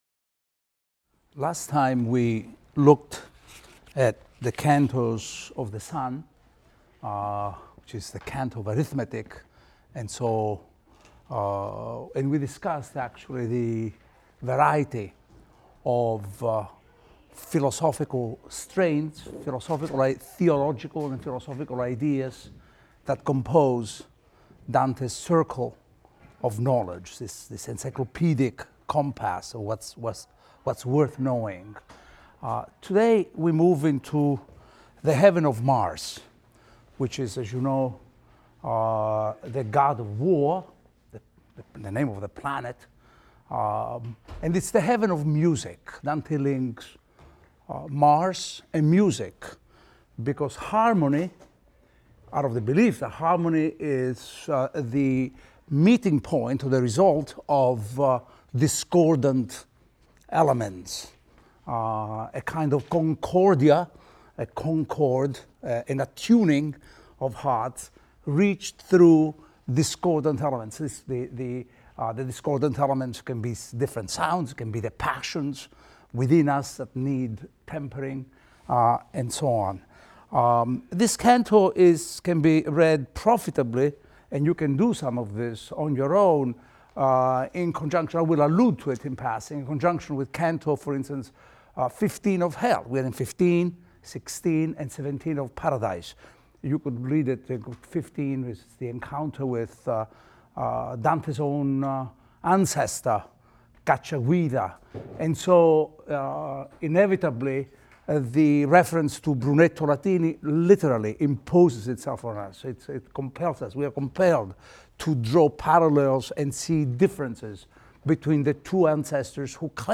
ITAL 310 - Lecture 19 - Paradise XV, XVI, XVII | Open Yale Courses